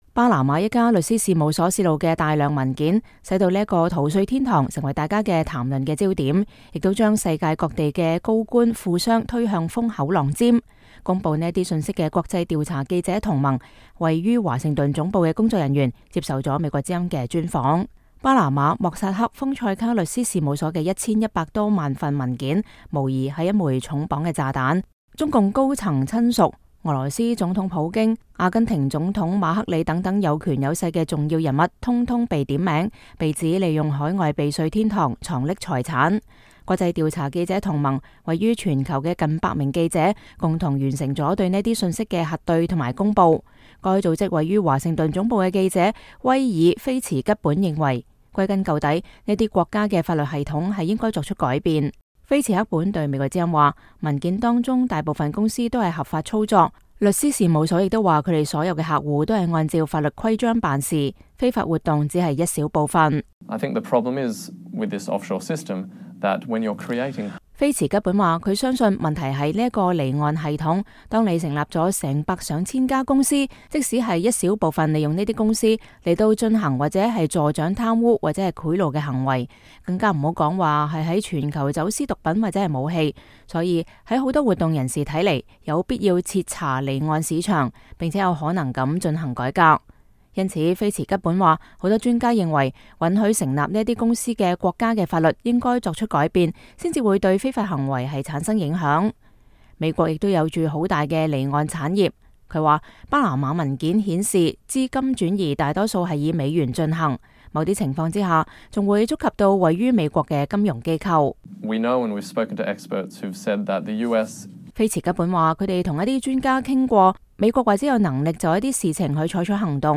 巴拿馬一家律師事務所洩露的大量文件使這個逃稅天堂成為人們談論的焦點，也將世界各地的高官富商推向風口浪尖。公佈這些信息的國際調查記者同盟(International Consortium of Investigative Journalists)位於華盛頓總部的工作人員接受了美國之音記者的專訪。